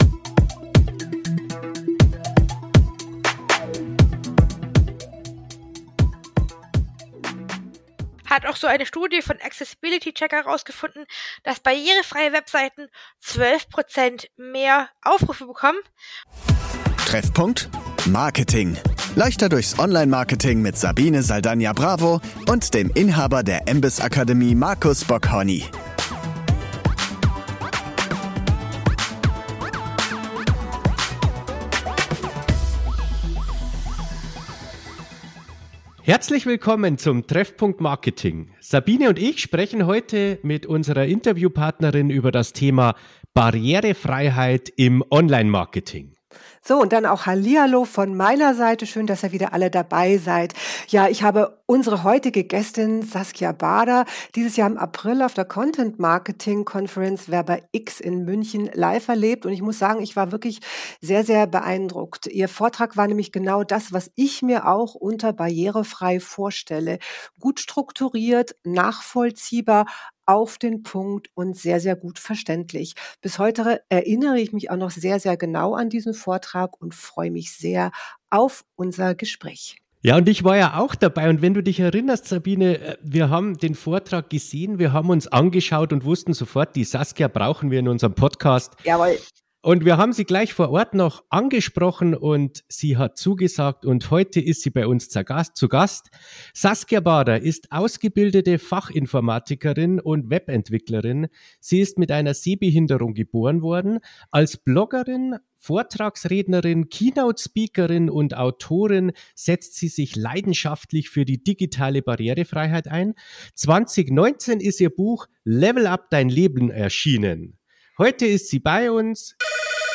Im lockeren Talk teilt sie mit uns ihre Erfahrungen und Herausforderungen im digitalen Raum.